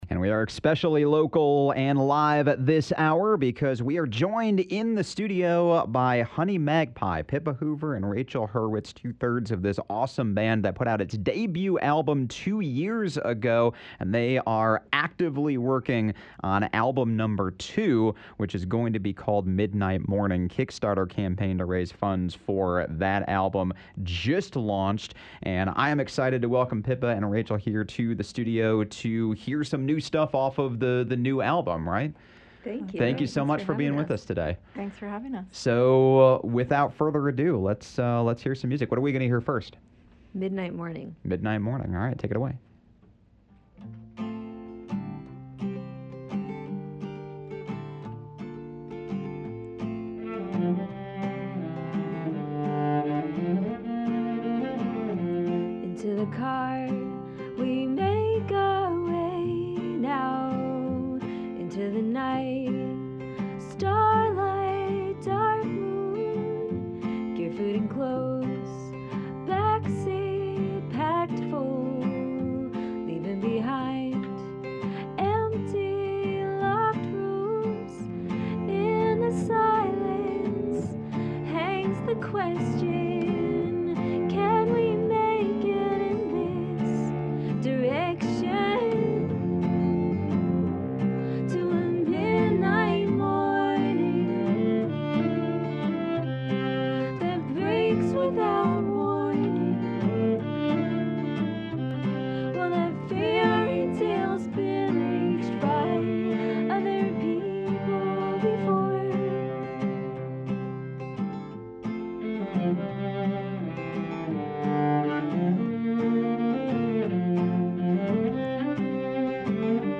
vocals, guitar, keyboard
cello, vocals
violin, vocals